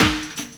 Index of /90_sSampleCDs/Best Service ProSamples vol.15 - Dance Drums [AKAI] 1CD/Partition A/SD 121-180